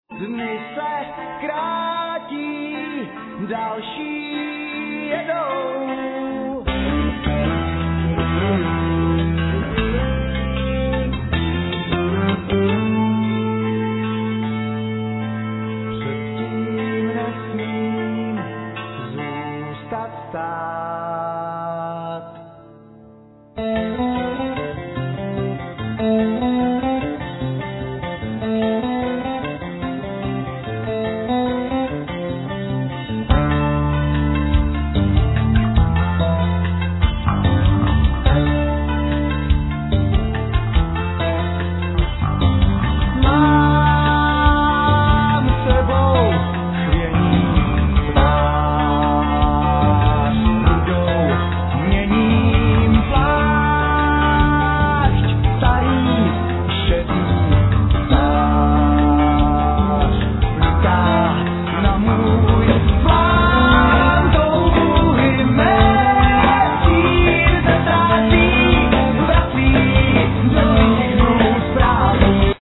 Voice, Chest Drum
Drums
Bass
Guitar
Cimbalum,Vocal
Percussions
Cello
Violin